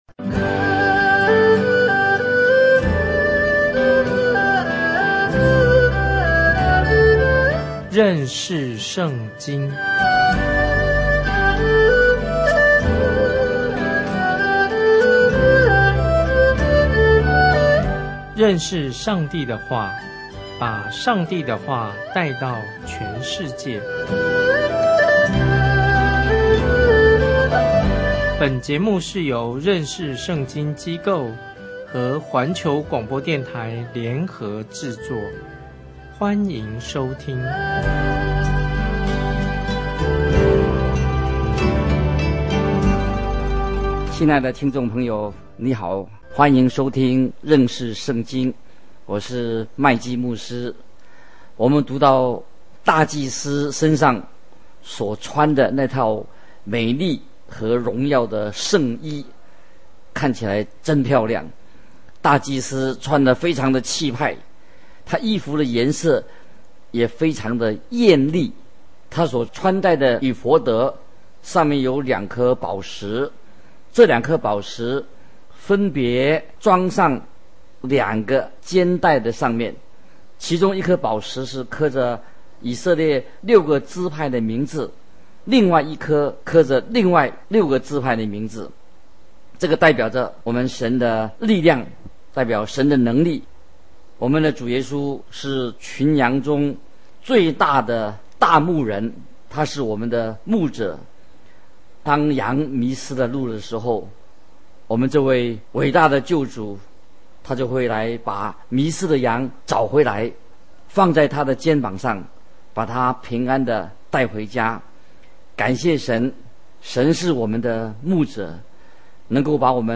這是個每天30分鐘的廣播節目，旨在帶領聽眾有系統地查考整本聖經。